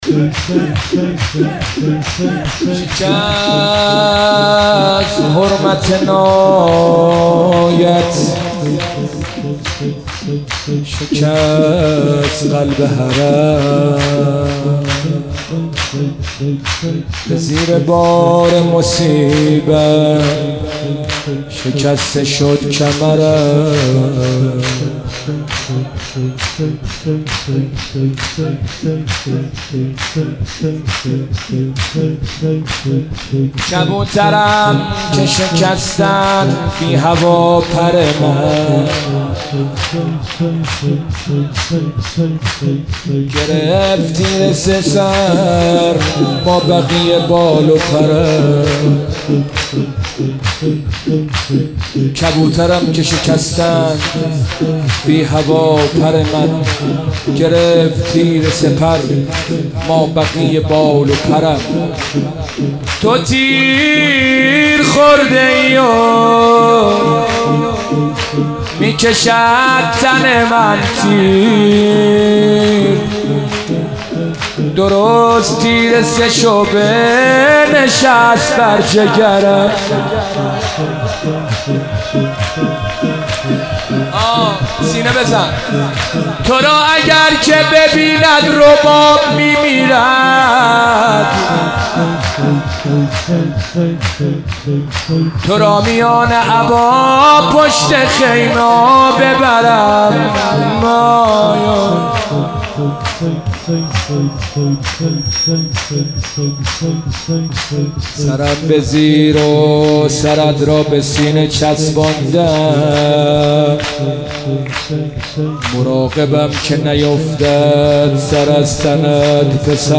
مناسبت : شب هفتم محرم
مداح : حسین سیب سرخی